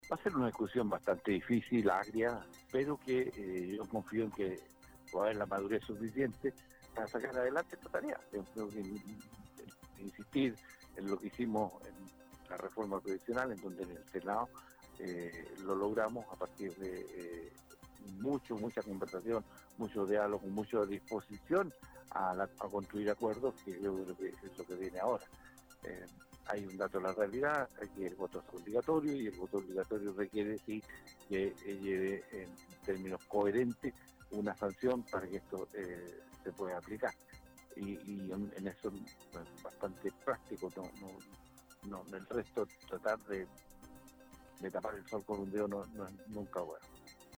En entrevista con Radio UdeC, Saavedra advirtió que, sin sanciones claras, la obligatoriedad del sufragio queda en entredicho.